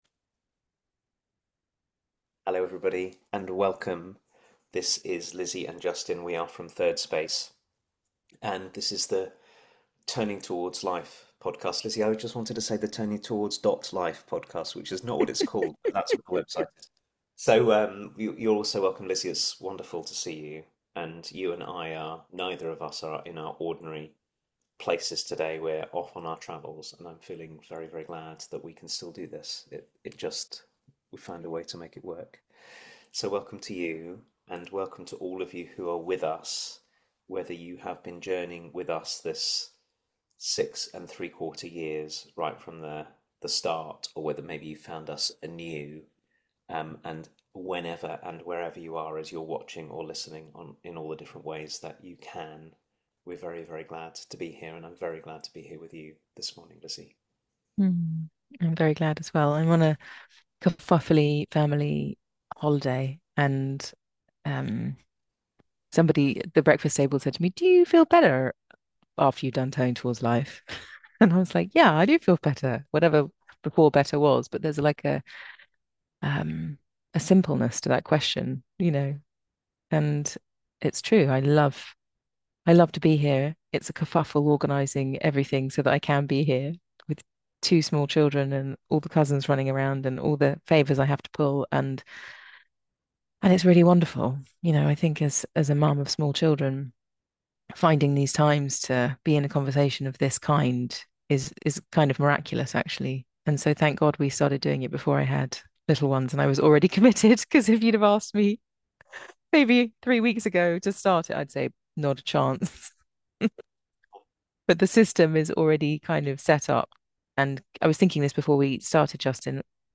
Turning Towards Life, a week-by-week conversation inviting us deeply into our lives, is a live 30 minute conversation